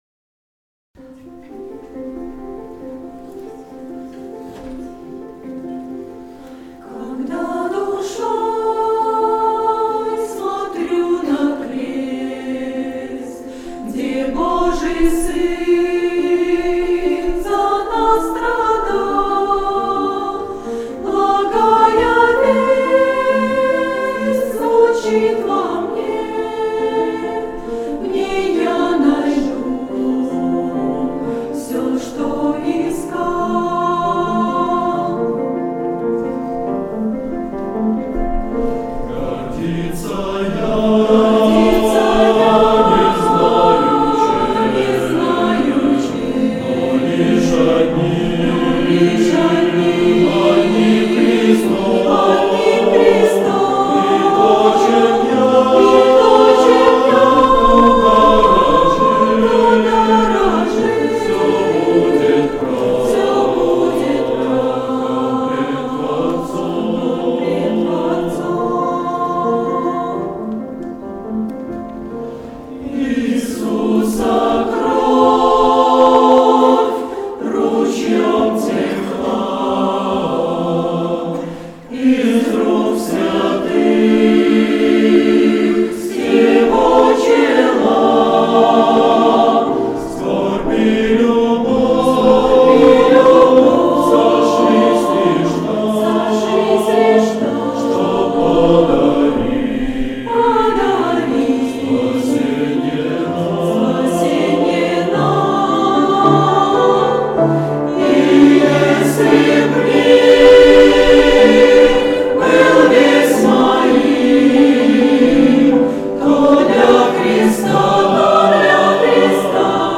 05-11-17 / Когда душой смотрю на крест (обр. для хора Джозеф Мартин, рус. ред. перевода Н. Ломако)
Хор